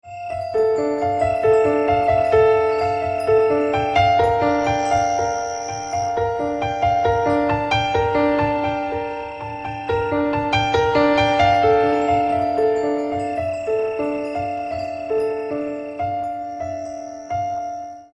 спокойные
без слов
красивая мелодия
пианино
нежные
сверчки
Классическая музыка от иранского музыканта